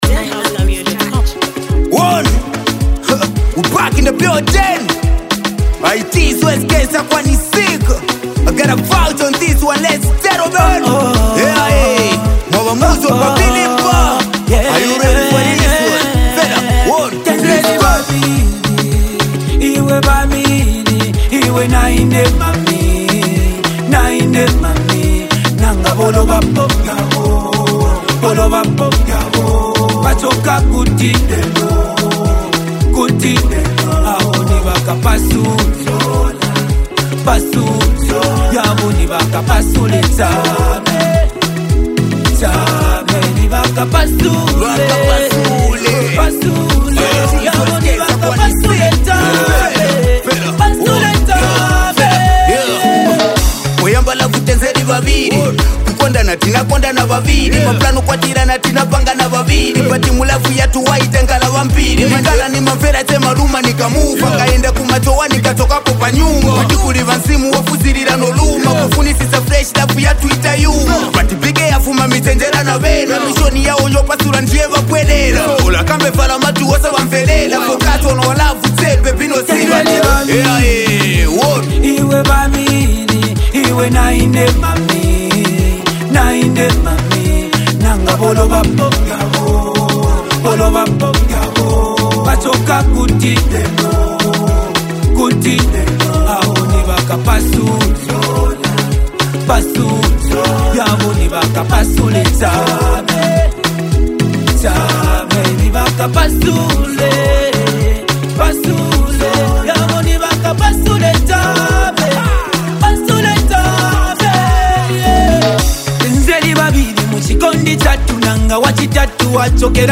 soulful collaboration